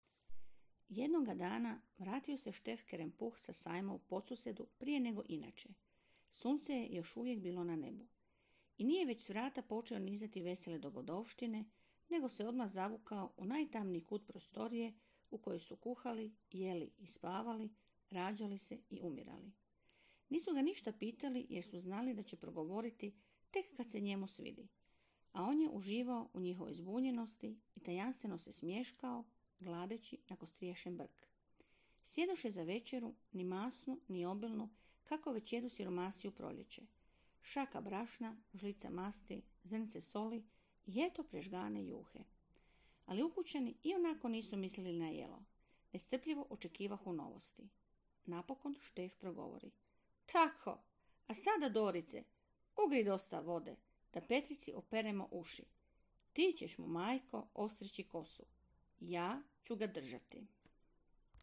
Jedan od načina obilježavanja Mjeseca hrvatske knjige je i Minuta za čitanje – akcija čitanja naglas.